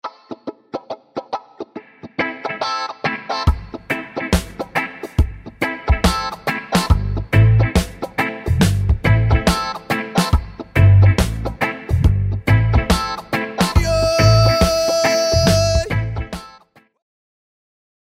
reggae
reggae.mp3